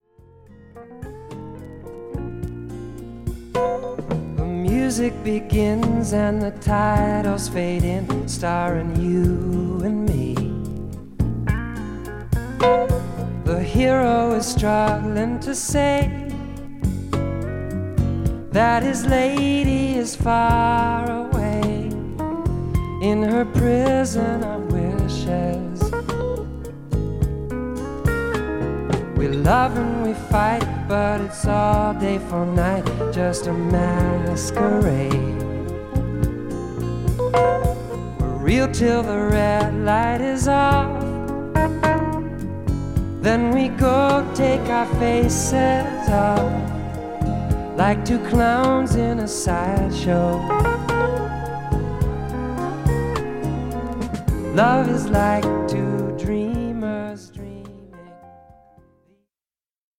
ジャズやフュージョン、ボサノヴァなどが絶妙な匙加減で混ざり合った、エバーグリーンな温かみを感じさせる名作です。